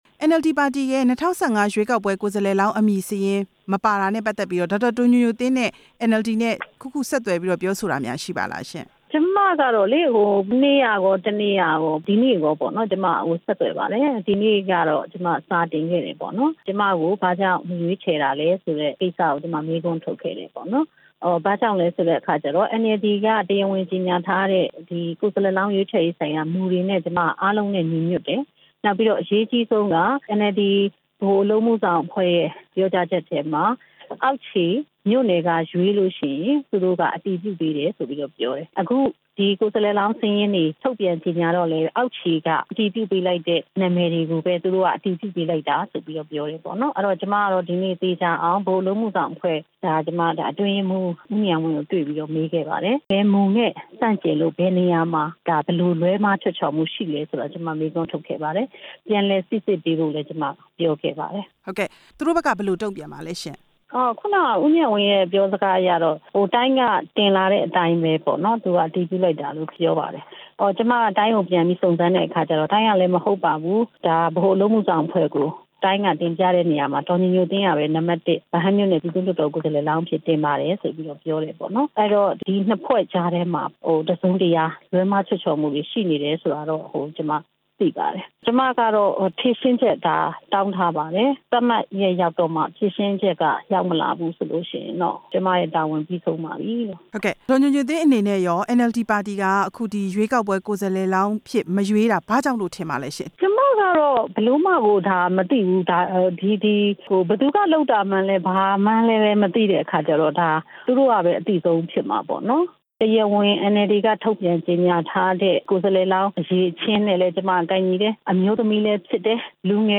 NLD ကိုယ်စားလှယ်လောင်း ကိစ္စ ဒေါက်တာ ဒေါ်ညိုညိုသင်းကို မေးမြန်းချက်